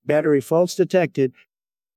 battery-faults-detected.wav